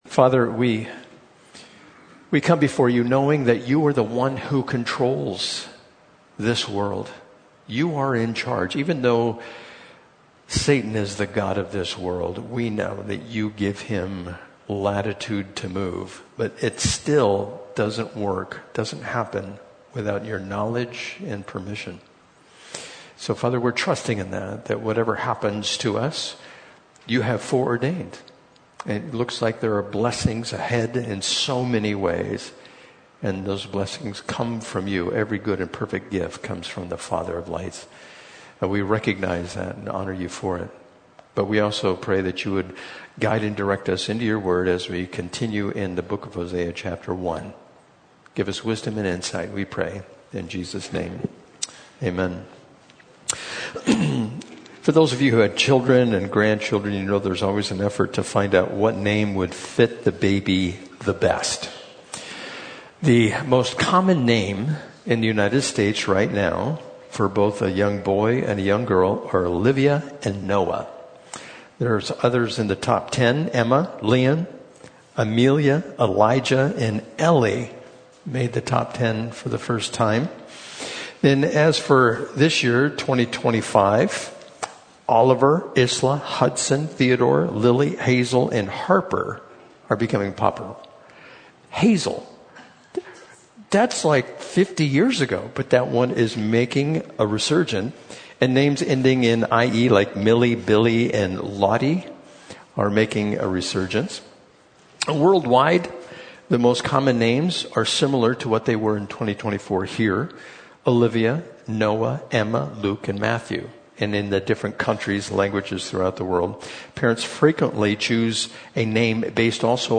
Audio Teachings of Calvary Chapel Lakeside